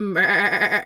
sheep_2_baa_calm_01.wav